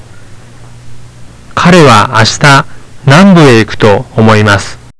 有聲發音